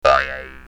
bounce3.mp3